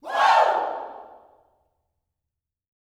WOO  04.wav